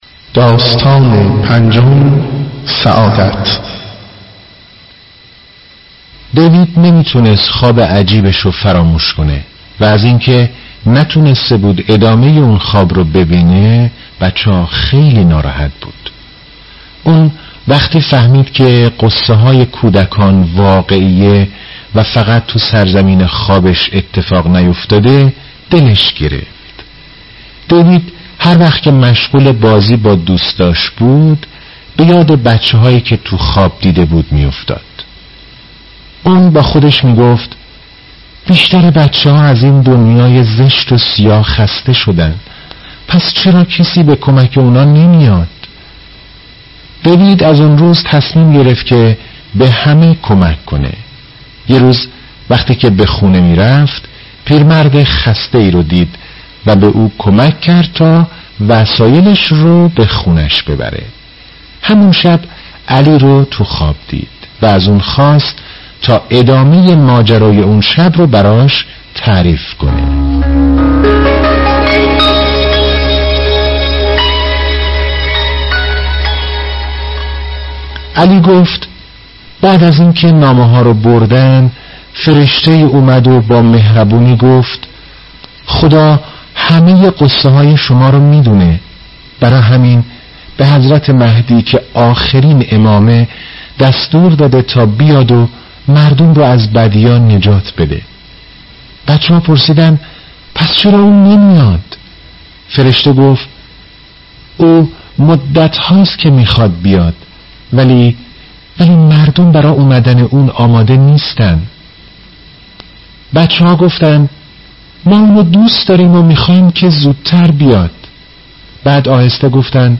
خوانش ده دقیقه ای کتاب دعبل و زلفا